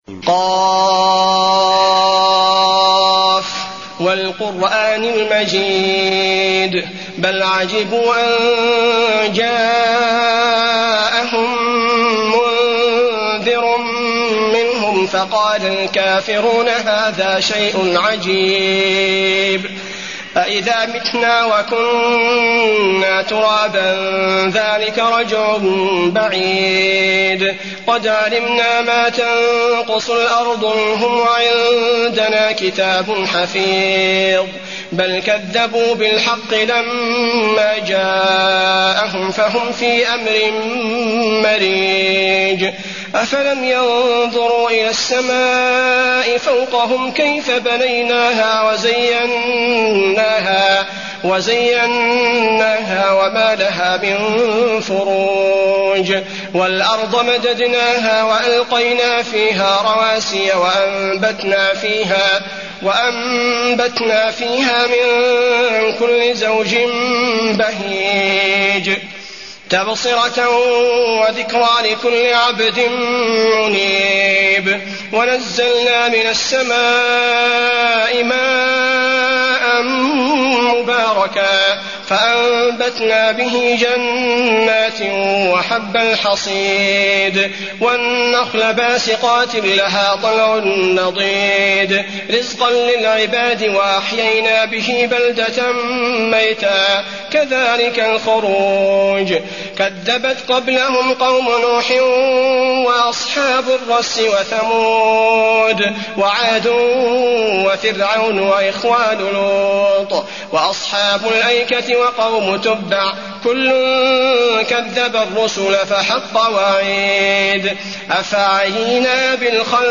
المكان: المسجد النبوي ق